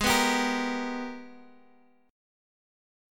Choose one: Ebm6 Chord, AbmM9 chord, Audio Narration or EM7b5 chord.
AbmM9 chord